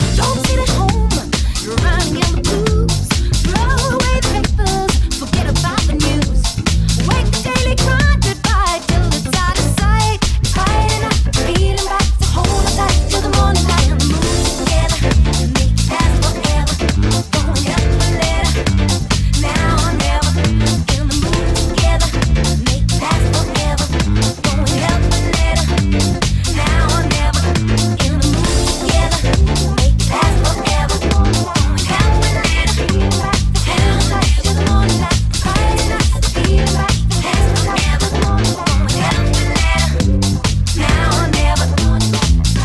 Genere: soulful, remix